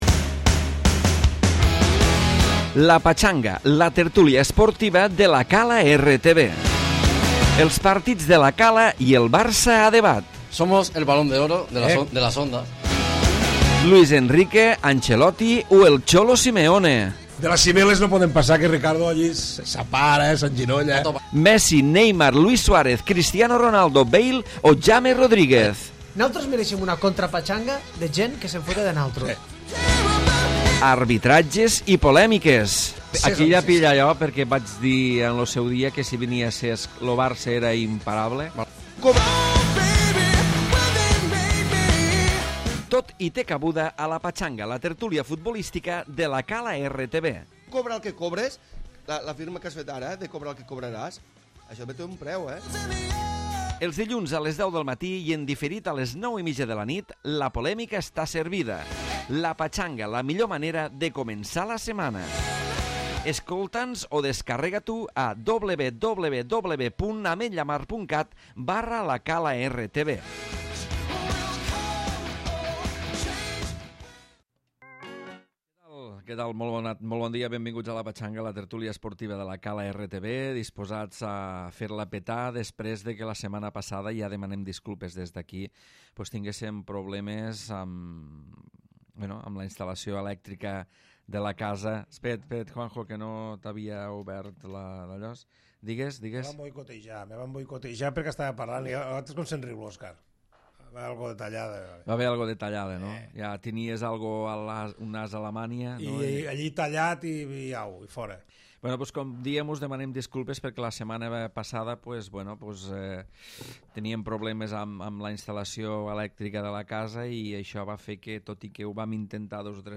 Tertúlia esportiva avui molt centrada en el pròxim classic del futbol entre el Madrid i el Barça amb porra de pronòstics inclosa.